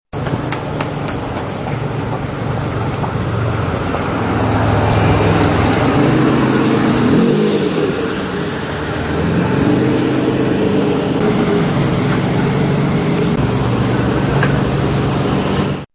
Cruising Then Parking And Opening Door